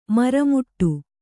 ♪ maramuṭṭu